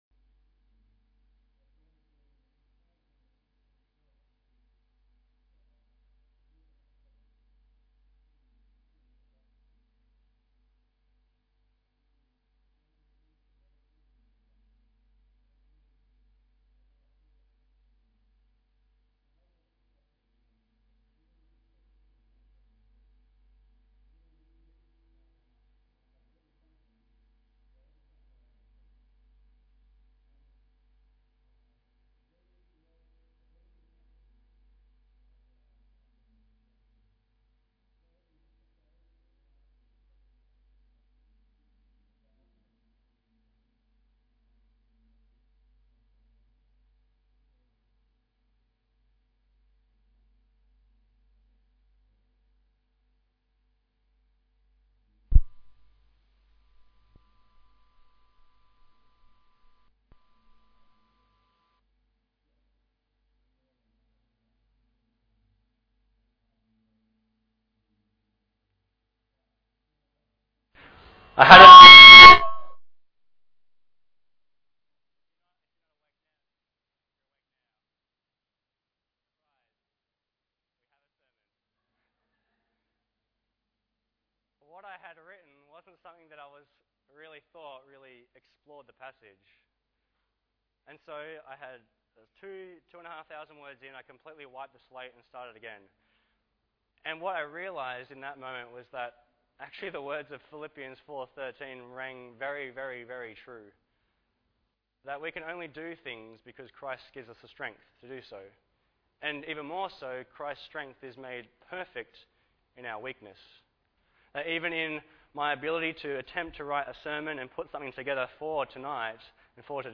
Bible Text: Philippians 4:4, 10-23 | Preacher